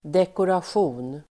Uttal: [dekorasj'o:n]